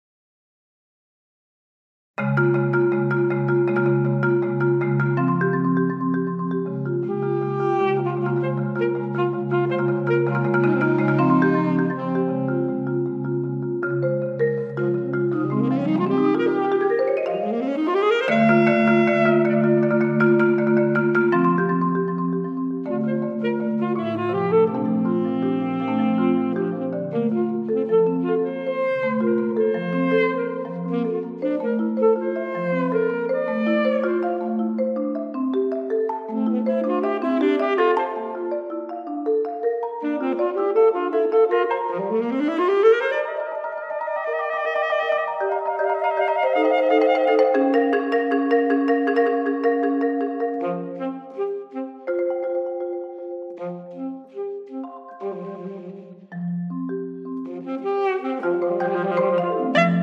Saxophone, Percussion